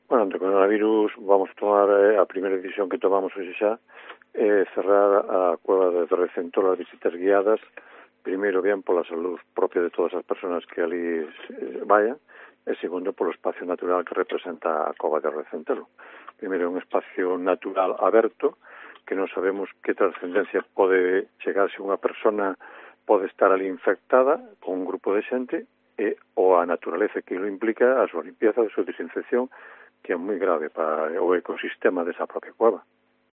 DECLARACIONES del alcalde de Mondoñedo, Manolo Otero